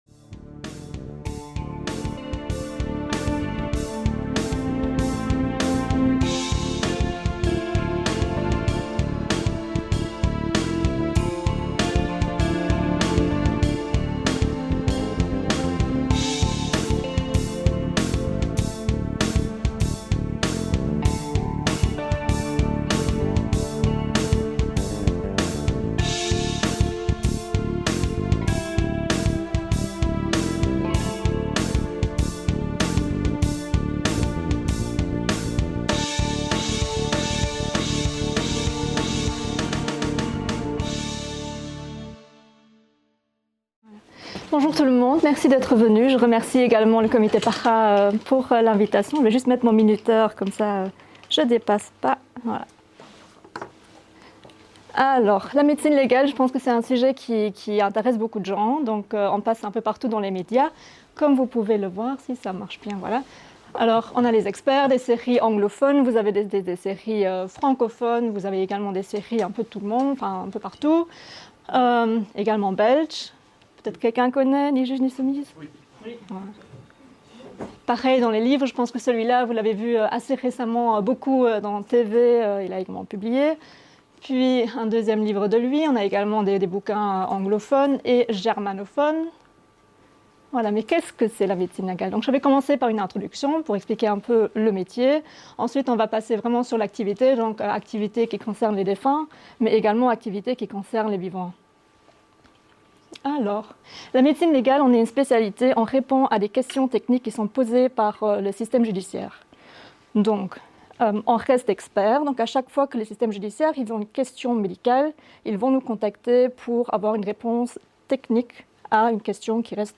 Cette conférence a été donnée le 30 mars 2024 dans le cadre des Skeptics in the Pub Bruxelles, un cycle de conférences mensuelles organisé par le Comité Para asbl.